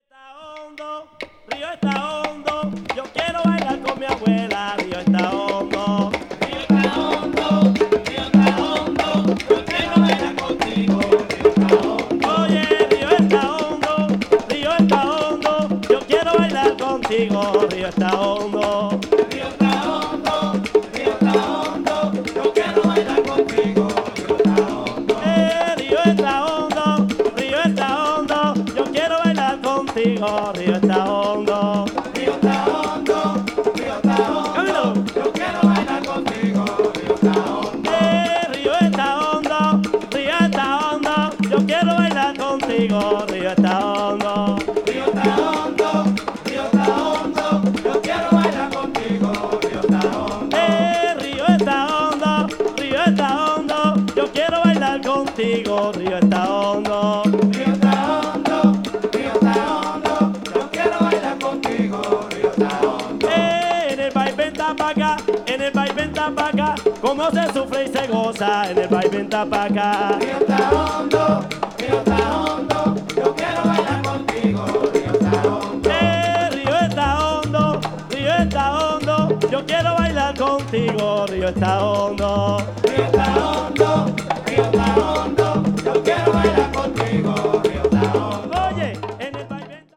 総勢12人のラティーノ達によるビッグ・バンドが繰り出すハイテンションかつエモーショナルなキューバン・ジャズが熱く展開。
ダイレクト・メタル・マスタリングによる臨場感のある音質もイイですね。
ethnic jazz   latin jazz   world music